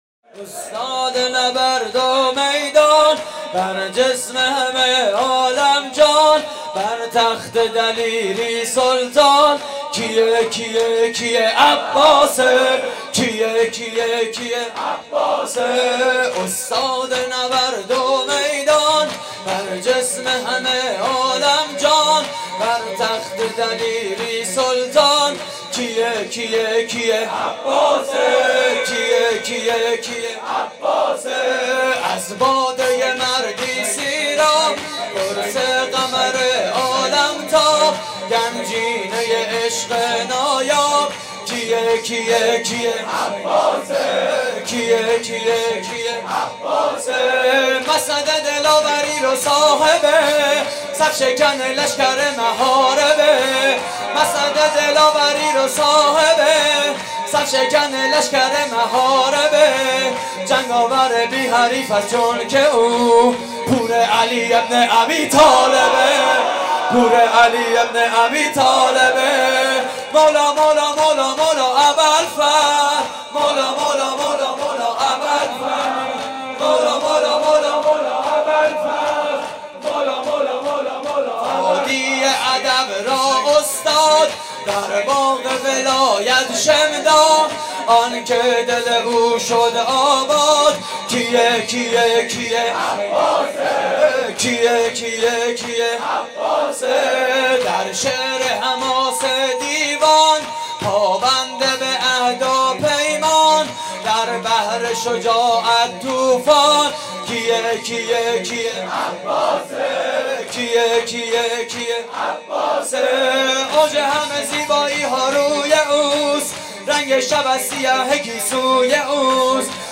شور: استاد نبرد و میدان
مراسم عزاداری شب تاسوعای حسینی (محرم 1433)